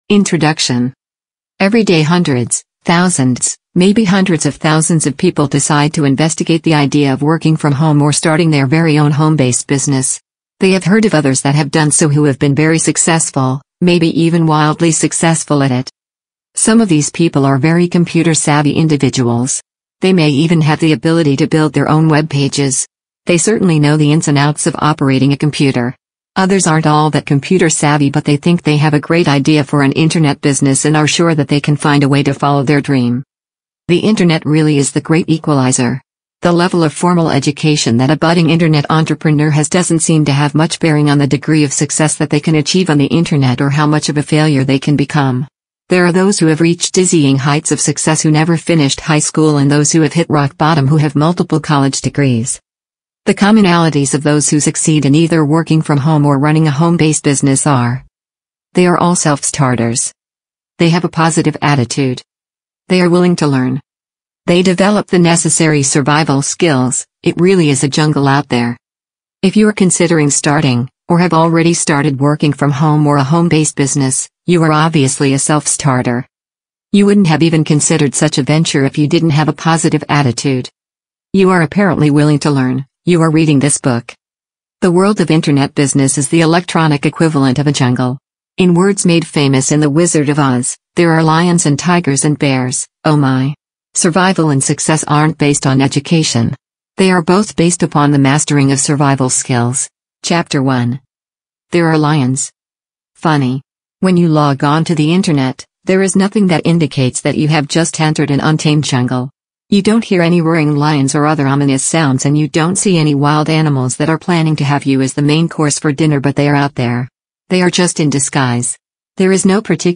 “How Working from Home” explores the evolving world of remote work — from productivity hacks and digital collaboration tools to the emotional and social sides of working outside a traditional office. Each episode features insightful conversations with entrepreneurs, freelancers, and experts who share their personal stories, practical strategies, and lessons learned from building successful remote careers.